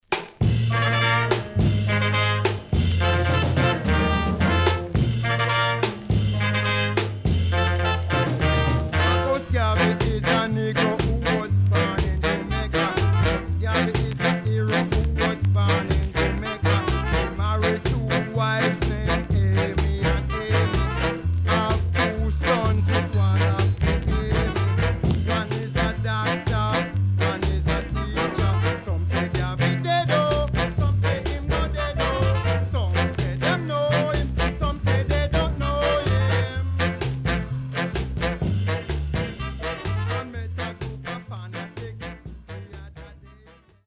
HOME > Back Order [VINTAGE 7inch]
CONDITION SIDE A:VG〜VG(OK)
SIDE A:全体的にチリノイズがあり、少しプチノイズ入ります。